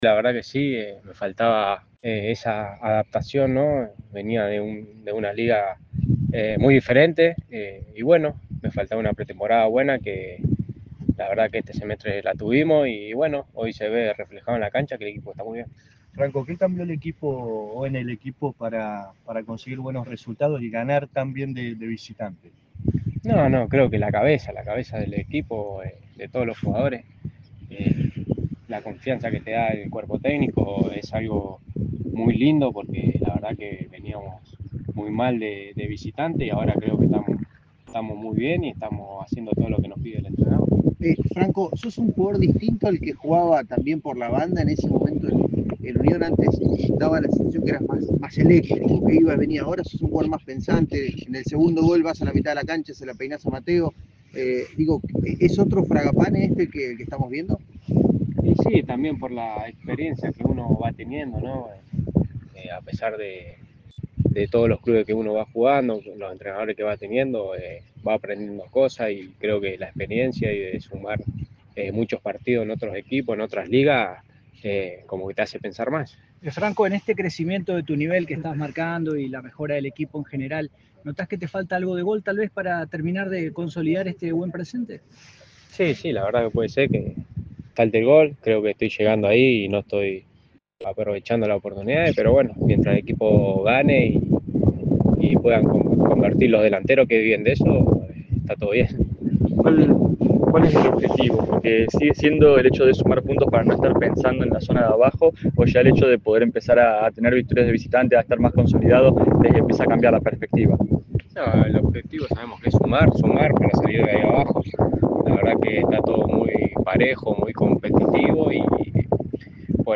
Luego del entrenamiento matutino en Casasol, el plantel de Unión tomó contacto con la prensa, y uno de los que habló fue Franco Fragapane. El futbolista rojiblanco habló de los motivos del cambio positivo que tuvo el equipo, de su situación personal y del objetivo general, entre otras cosas.